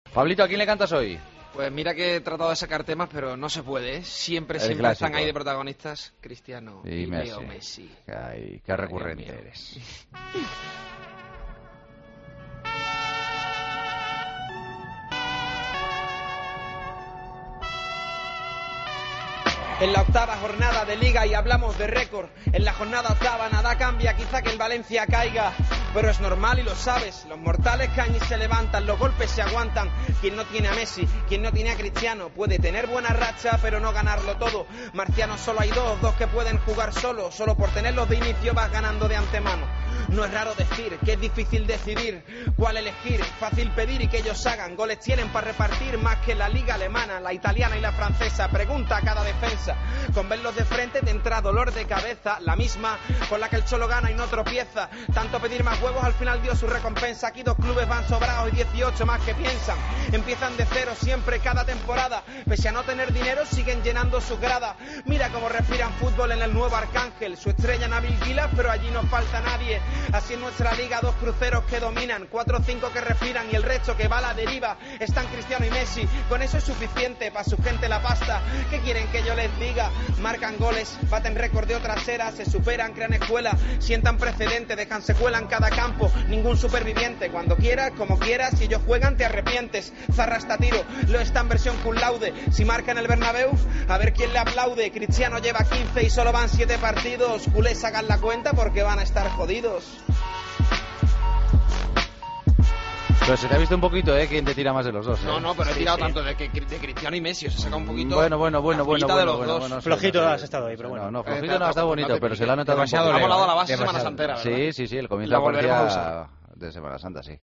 a ritmo de rap